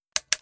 FlashlightSwitchOff.wav